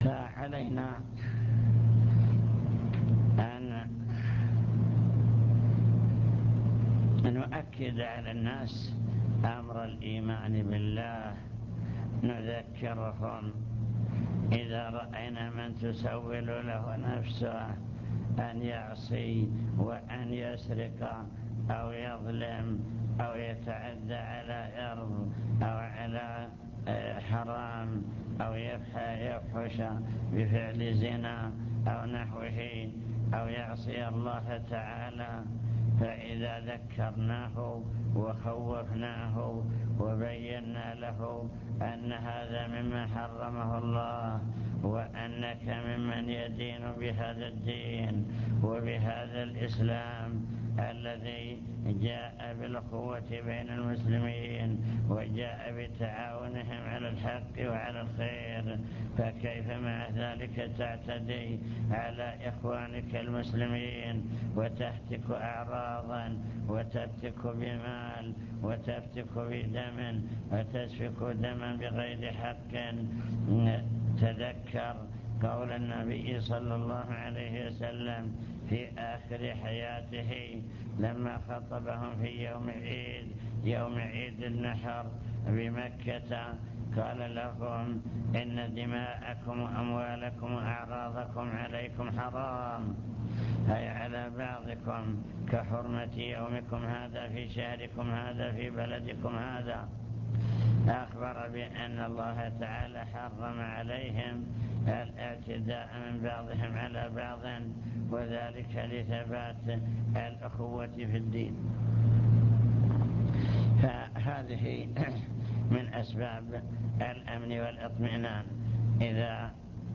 المكتبة الصوتية  تسجيلات - محاضرات ودروس  محاضرة في بدر بعنوان: وصايا عامة